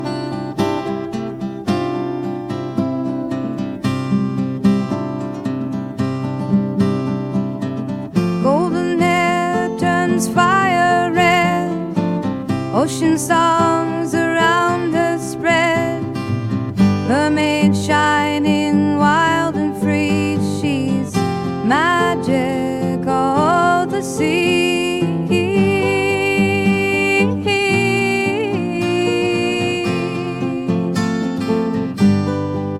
Well let’s try creating a short AI song.
These were Folk and Soft Rock.